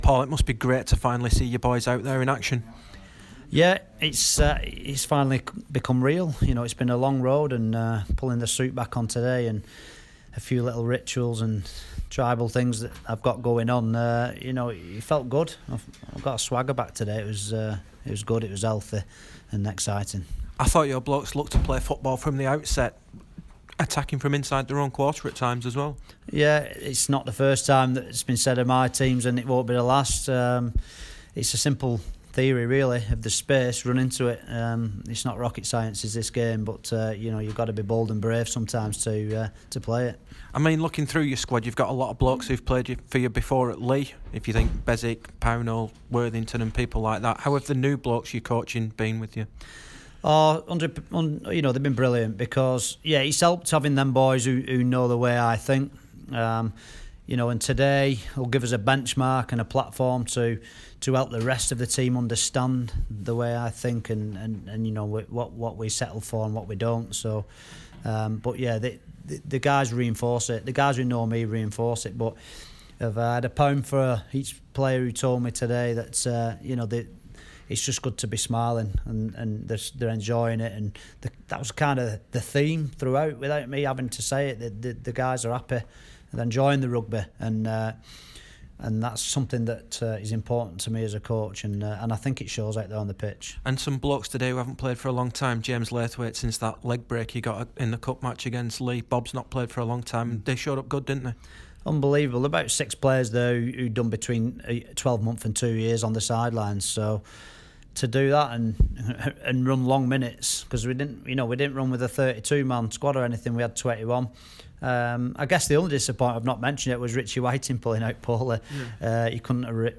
spoke after the game